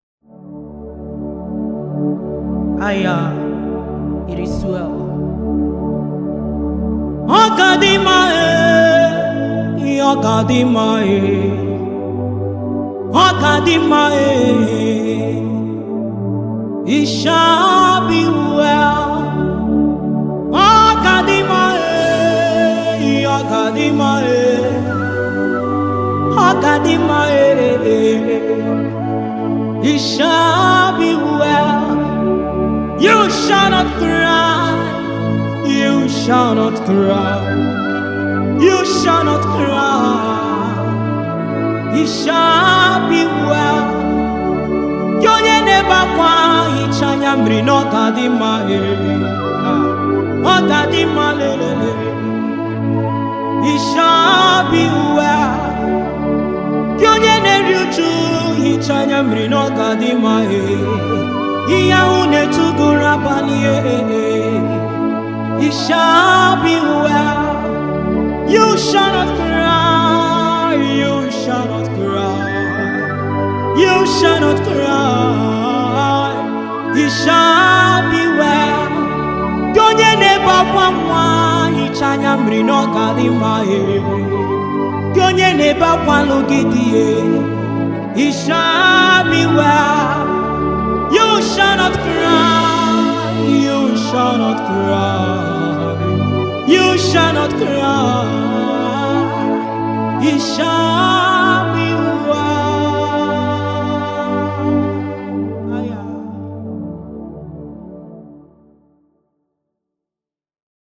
February 19, 2025 Publisher 01 Gospel 0